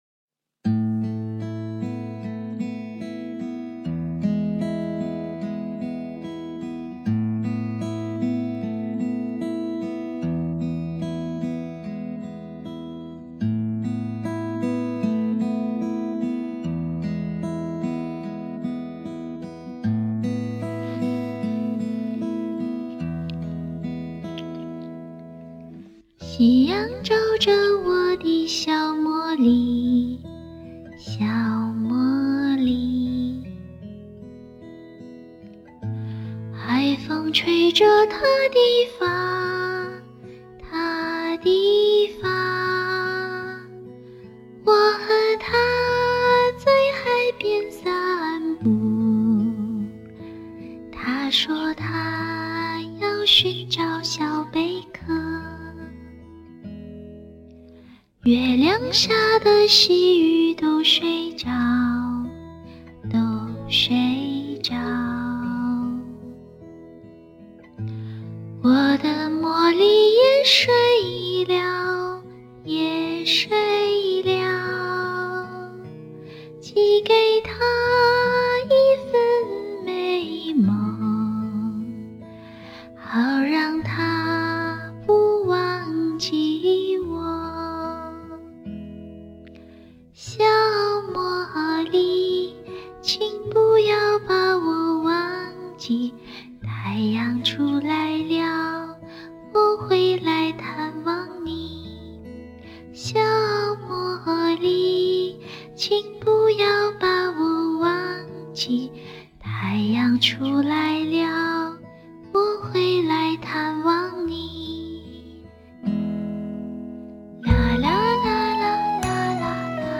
传说中的5岁来了，嘿嘿。
一首儿歌，最早好像是在文艺电影《路边野餐》中听到的。